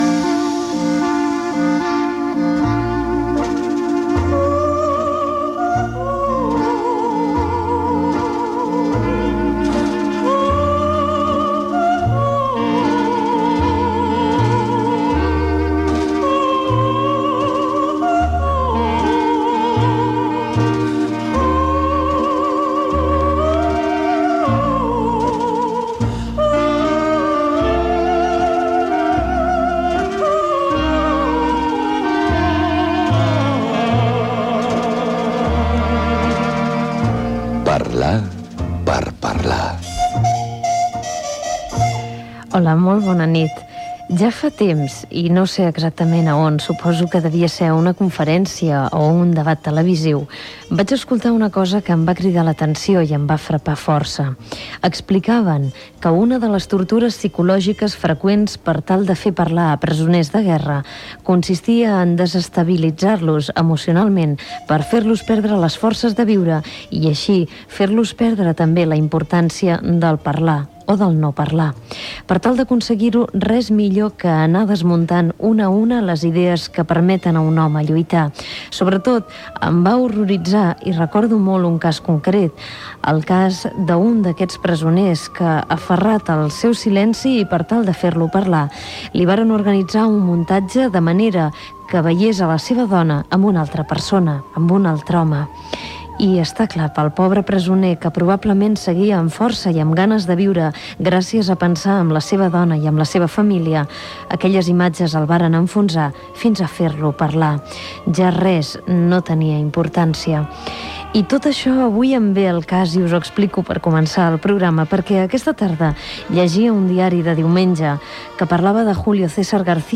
74044fe900f343d3f901e0d70747aec48b833b0d.mp3 Títol SER Catalunya Emissora Ràdio Barcelona 2 Cadena SER Titularitat Privada estatal Nom programa Parlar per parlar Descripció Careta del programa, comentari introductori sobre el parlar, els suïcidis de joves, telèfon del programa, trucada telefònica d'una persona que parla sobre els tipus de suïcidi. Gènere radiofònic Participació